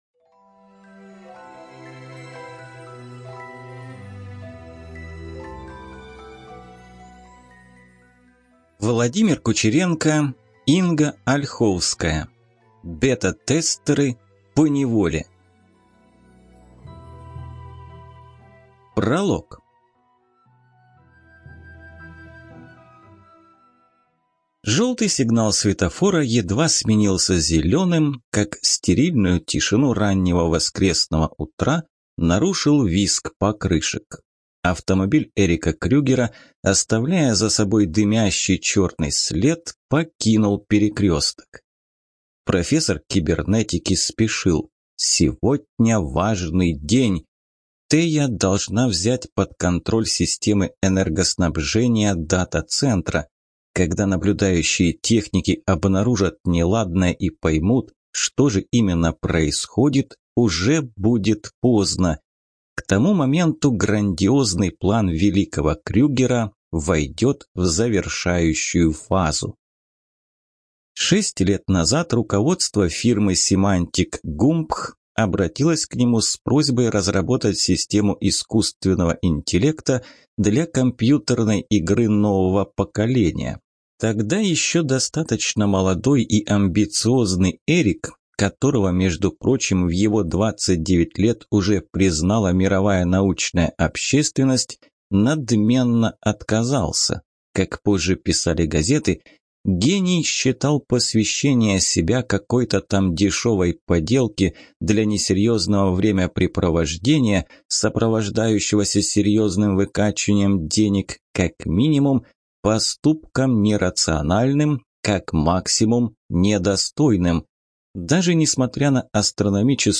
ЖанрЮмор и сатира, Фэнтези